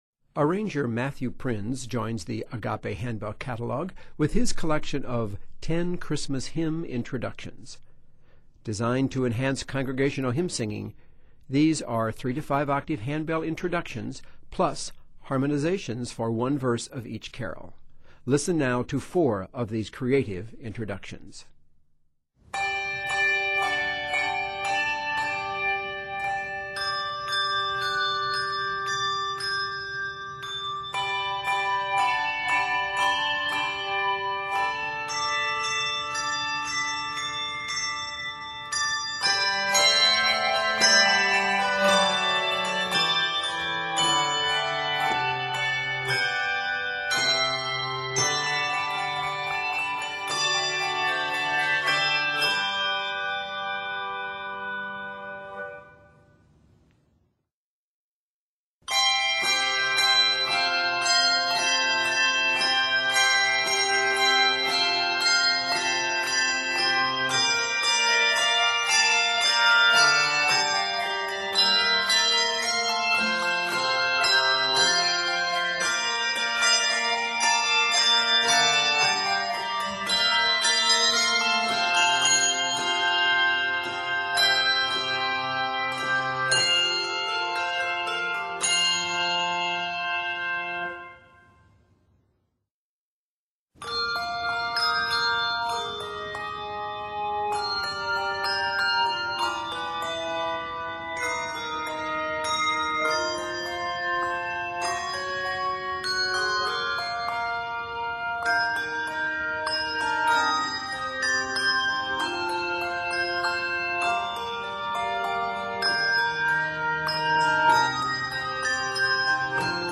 3-5 octave handbell introductions